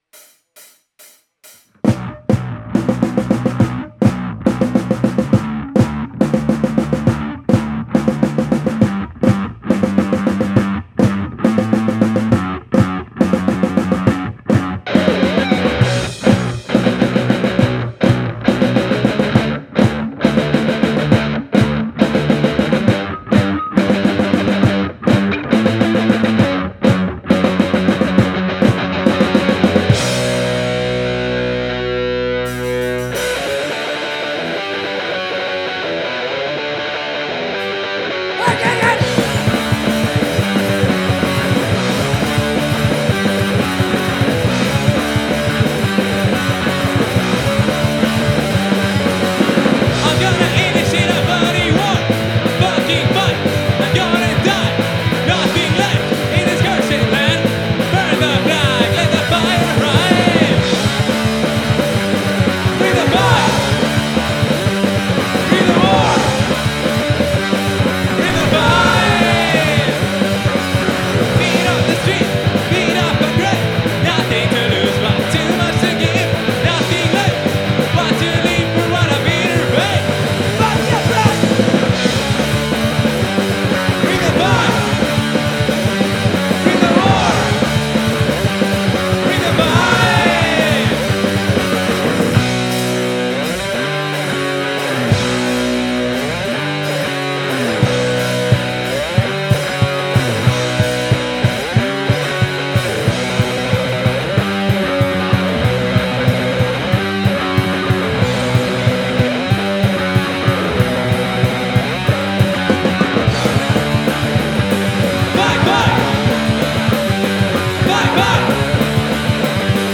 Banda caraqueña de punk llena de energía.